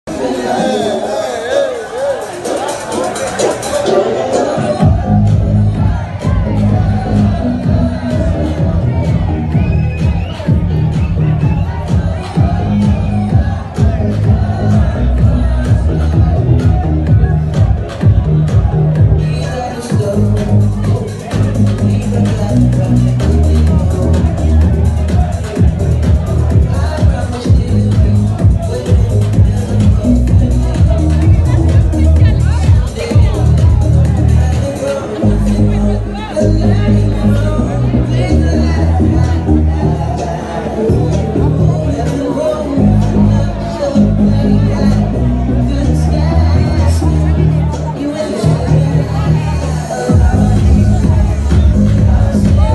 Warehouse energy, underground sound.